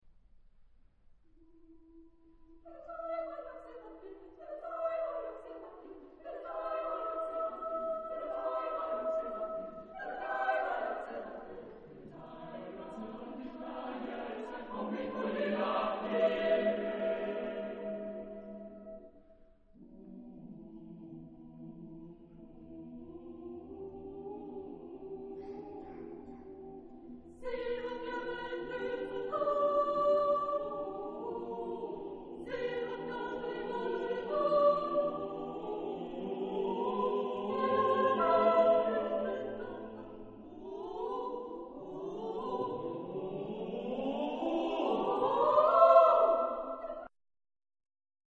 Género/Estilo/Forma: Profano ; Canción
Tipo de formación coral: SATB  (4 voces Coro mixto )
Tonalidad : sol mayor
Ref. discográfica: Internationaler Kammerchor Wettbewerb Marktoberdorf